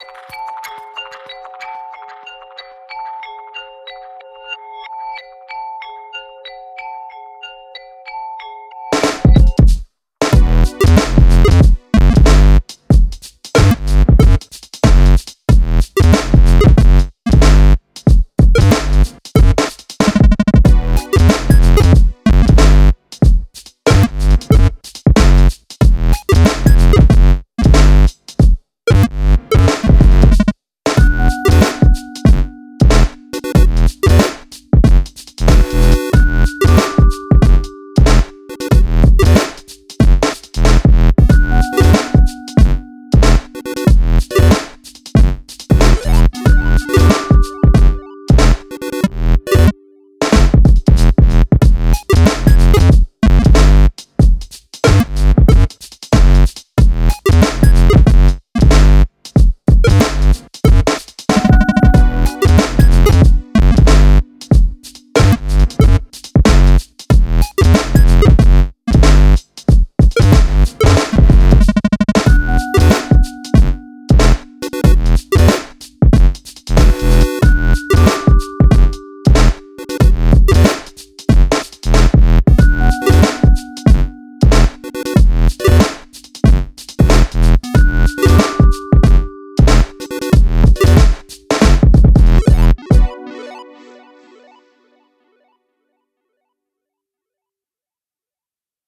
Urban nights electronica with offbeat video game feel.